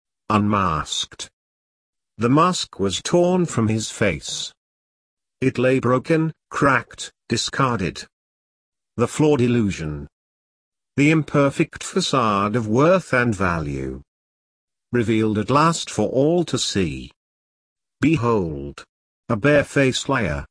I regret that the voice is robotic, but I like my poems voiced!